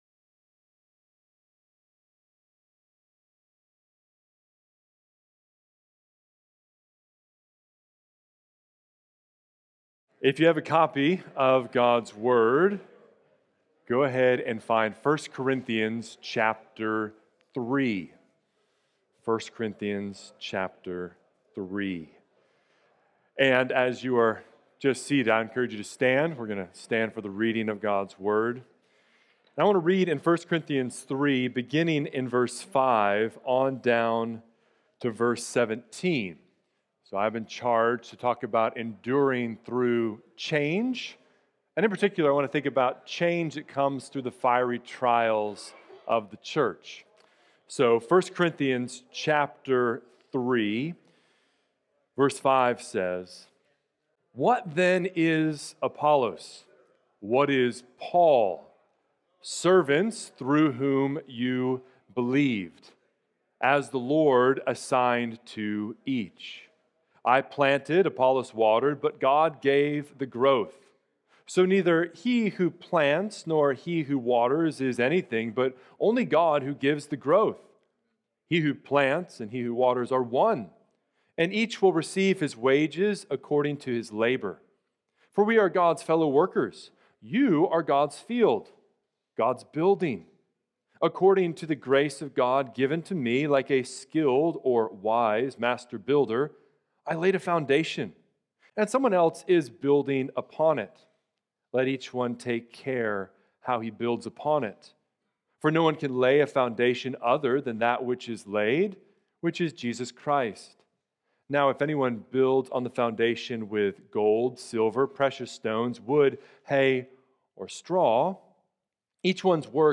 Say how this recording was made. The Necessity of Pastoral Endurance" 2025 Pastors' Seminar in Woodbridge, Virginia.